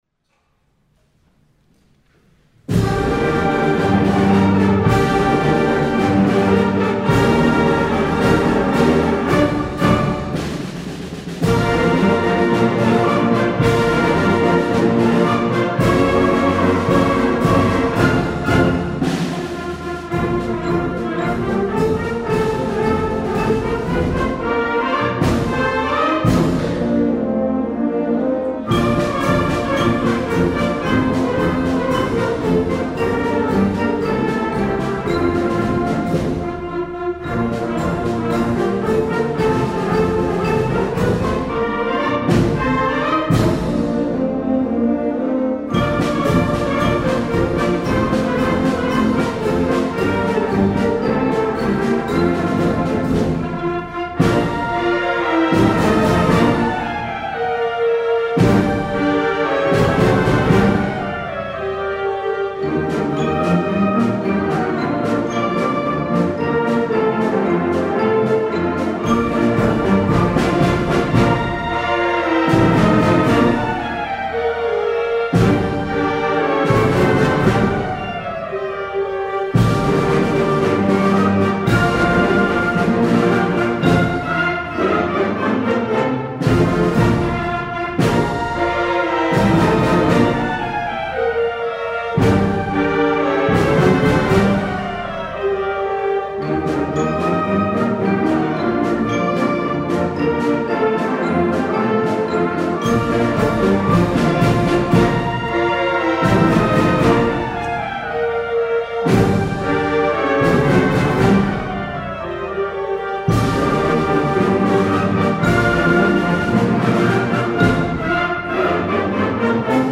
2018 Charter Oak Music Festival
Concert Band